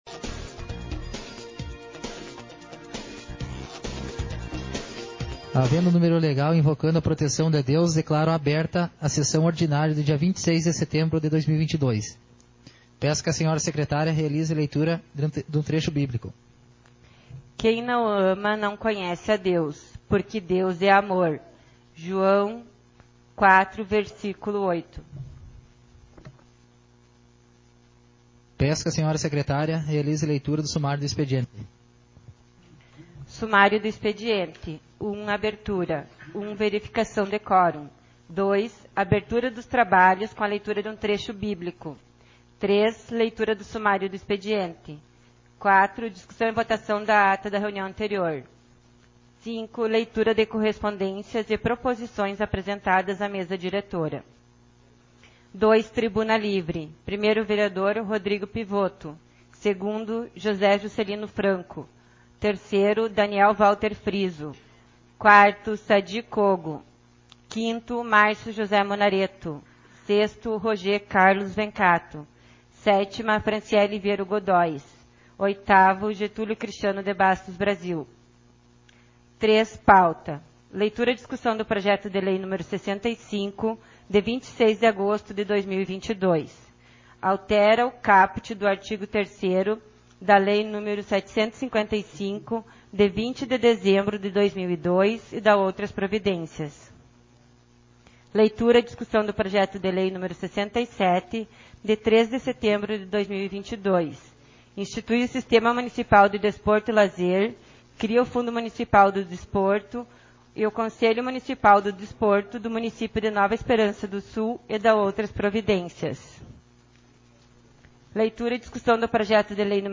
audios sessão — Câmara Municipal de Vereadores